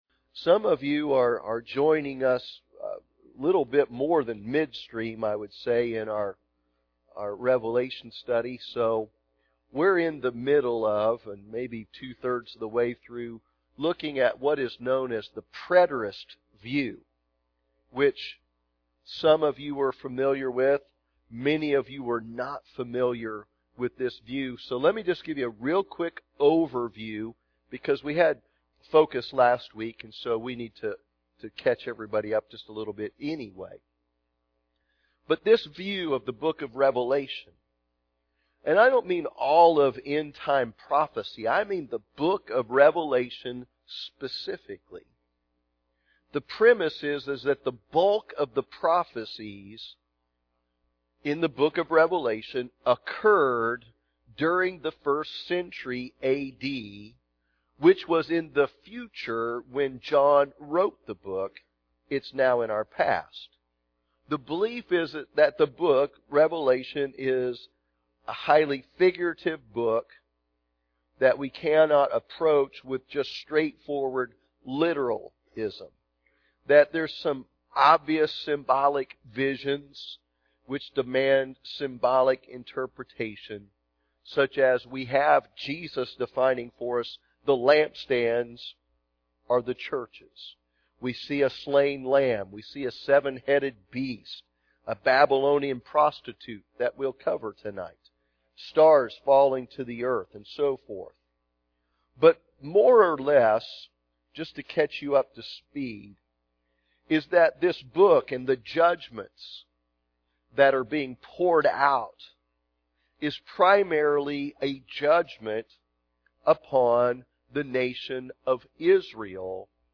The Four Views of Revelation Wednesday Evening Service